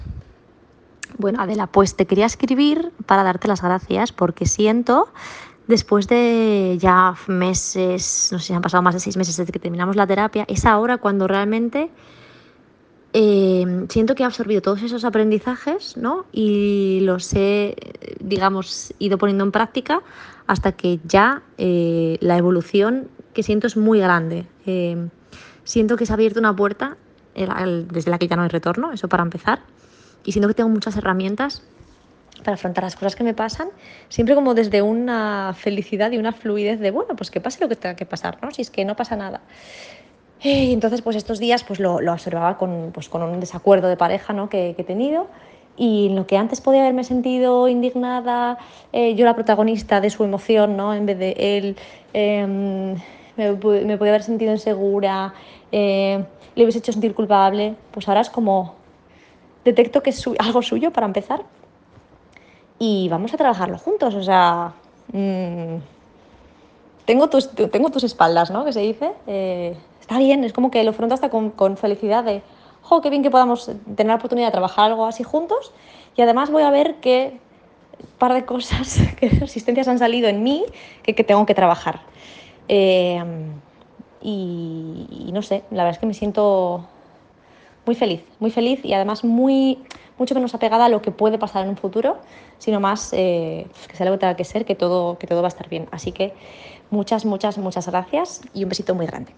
Y así lo sienten algunos de mis alumnos